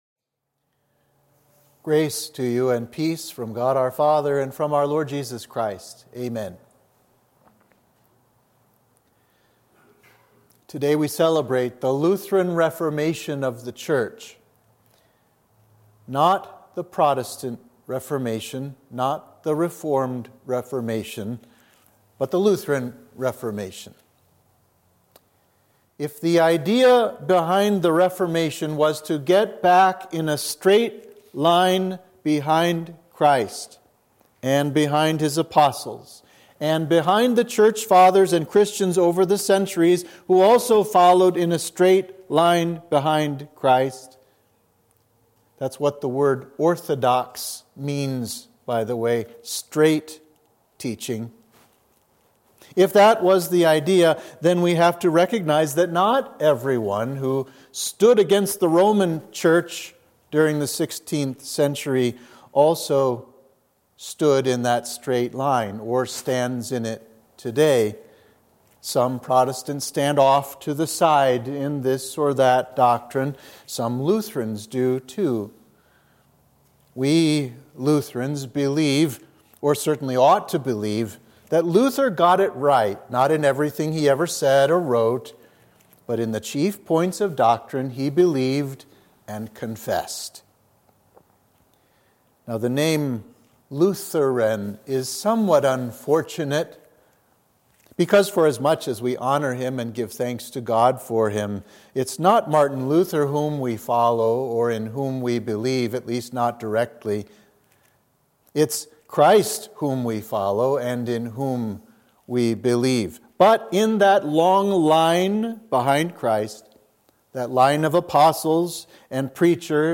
Sermon for the Festival of the Lutheran Reformation